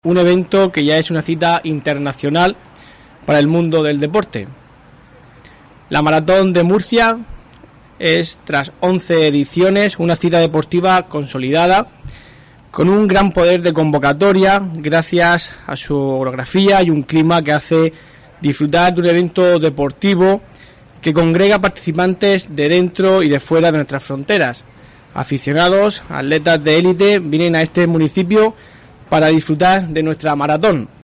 Miguel Ángel Noguera, concejal de Deportes del Ayuntamiento de Murcia